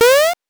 powerup_6.wav